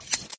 Shear Sheep